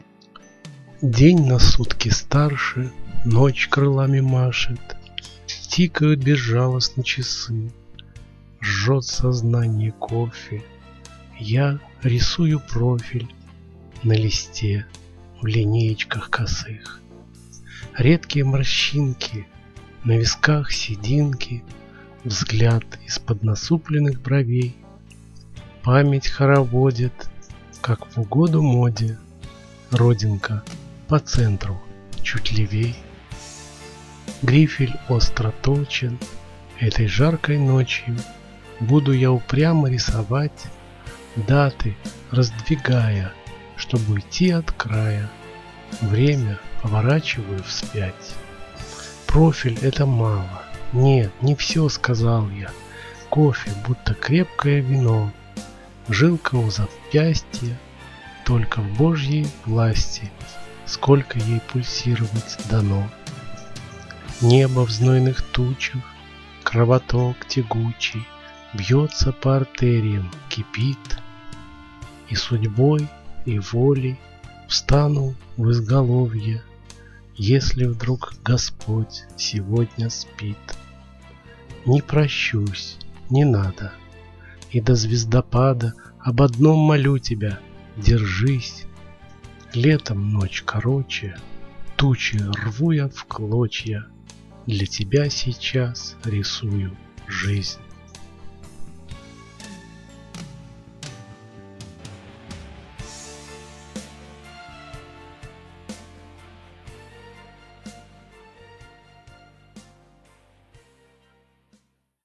ДЕКЛАМАЦИЯ